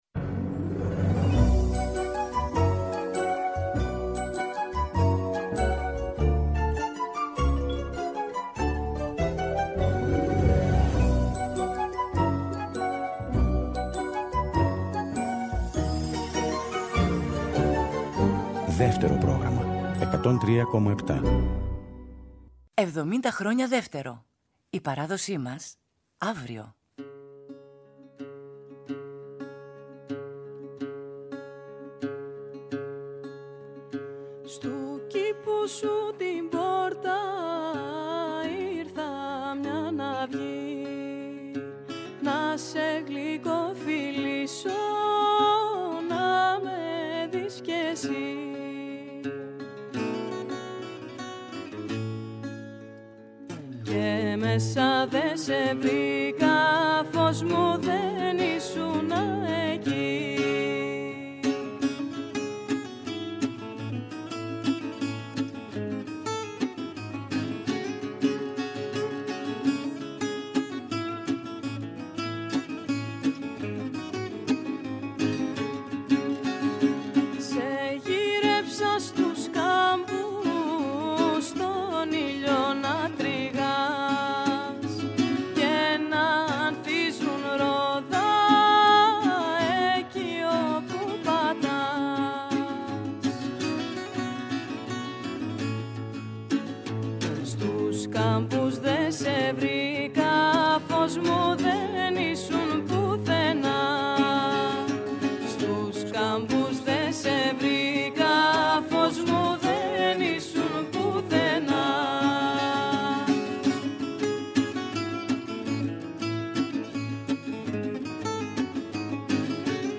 Με κιθάρα, σαντούρι, λύρα, τουμπί αλλά και πνευστά
τραγούδι- κρουστά